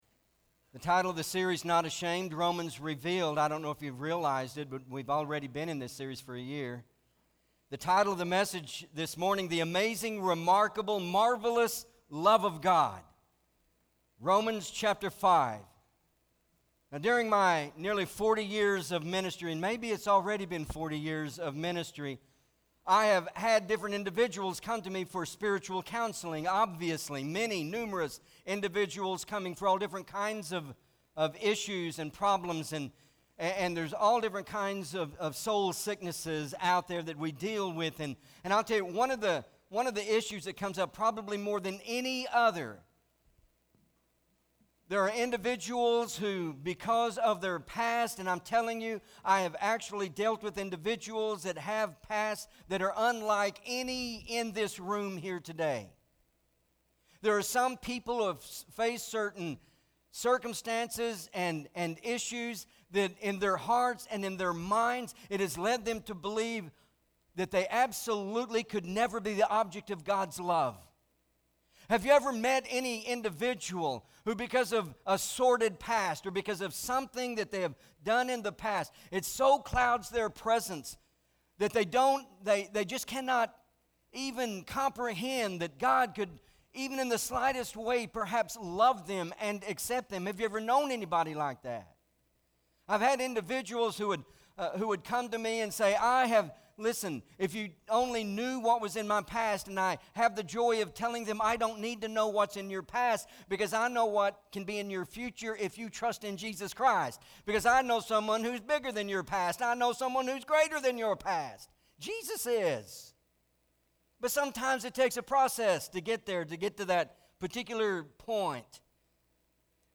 MP3 SUBSCRIBE on iTunes(Podcast) Notes Sermons in this Series Romans 5: 6-11 Not Ashamed!